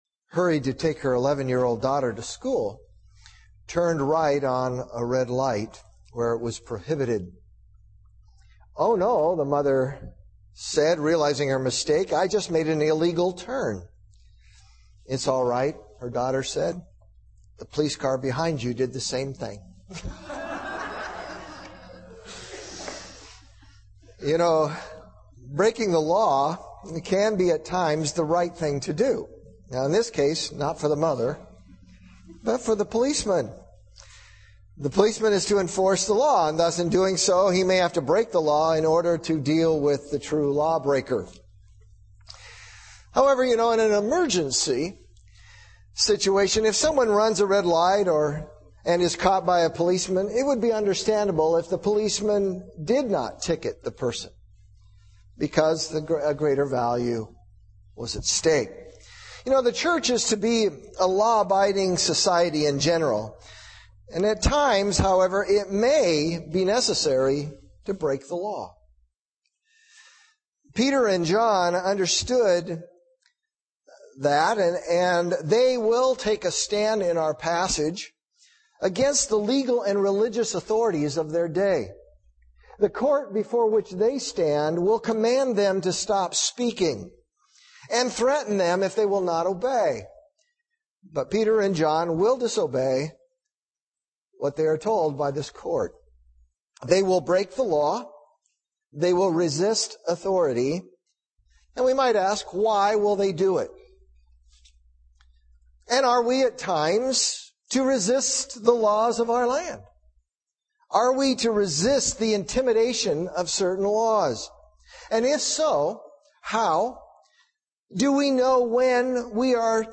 Acts 4:1-22 Preacher